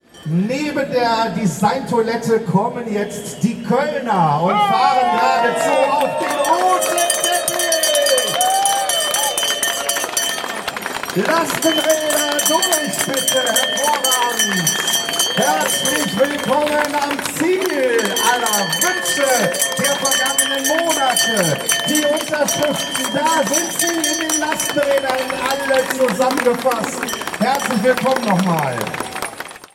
Mit großer zeitlicher Verzögerung kam die Kölner Gruppe der RadlerInnen mit der Unterschriftensammlung der Volksinitiative „Aufbruch Fahrrad“ in Düsseldorf an und wurde mit großem Applaus empfangen.
Der kraftvolle Empfang der Kölner RadlerInnen mit der Unterschriftensammlung der Volksinitiative „Aufbruch Fahrrad“ (Audio 6/8) [MP3]